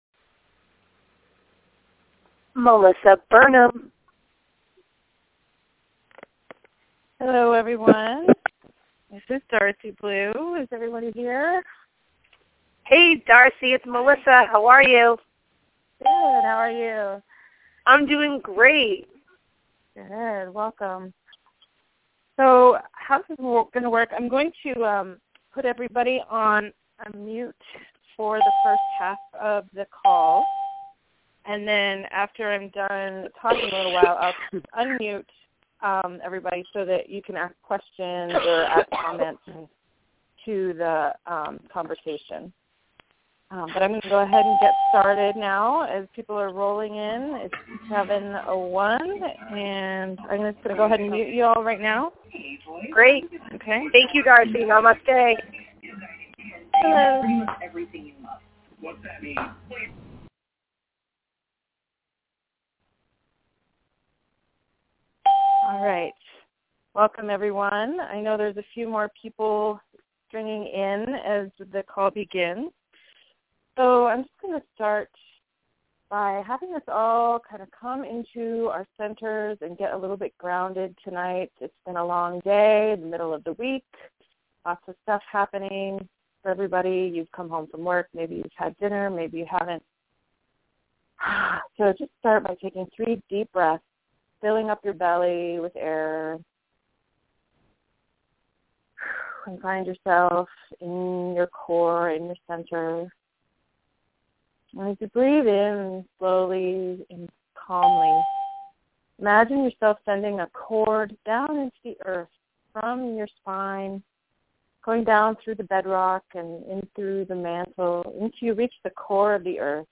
Last week I hosted a free class on Shamanic Herbalism- if you missed it, you can listen to the recording to find out what the heck I mean when I talk about Shamanic Herbalism.
what-the-heck-is-shamanic-herbalism-conference.mp3